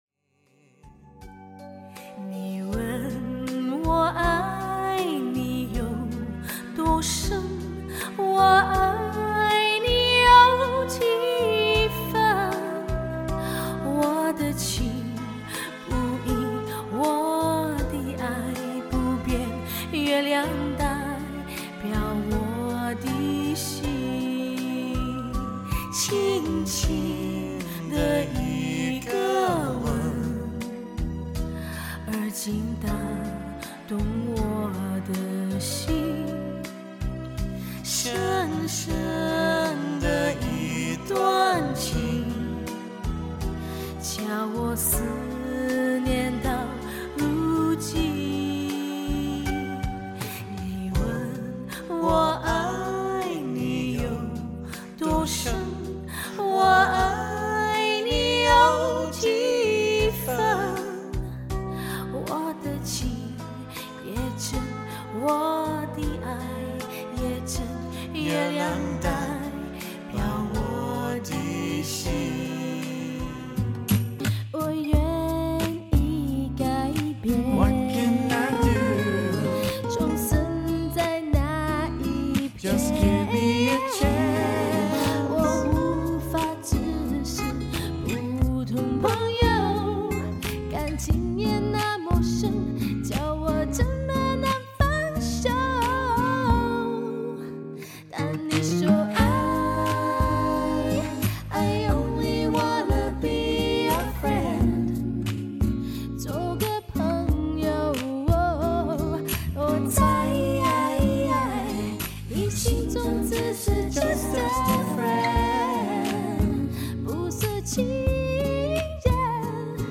Female
Singing
Duo Singing In Mandarin